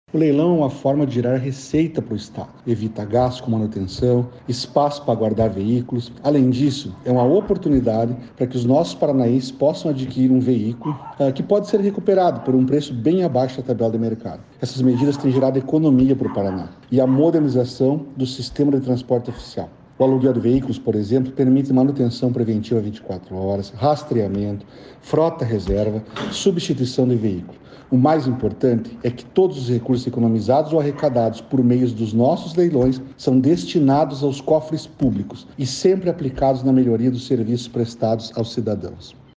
Sonora do secretário da Administração e da Previdência, Elisandro Frigo, sobre o novo leilão de veículos recuperáveis do Governo do Estado